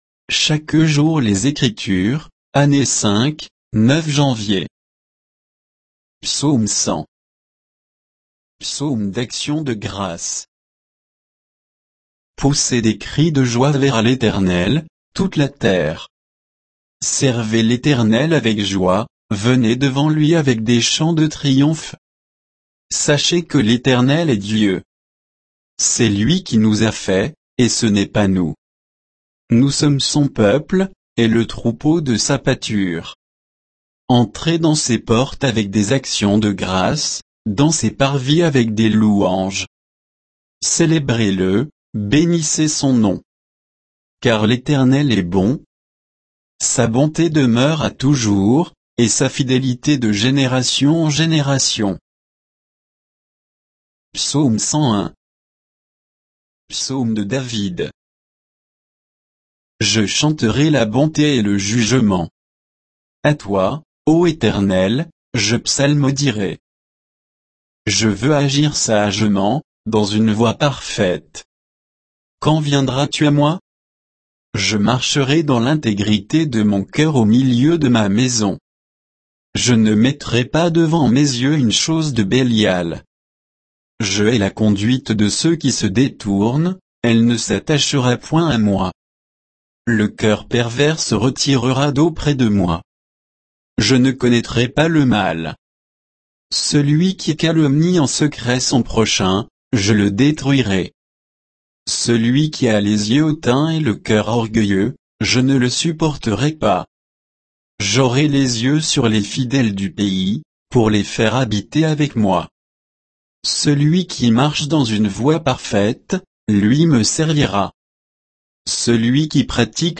Méditation quoditienne de Chaque jour les Écritures sur Psaumes 100 et 101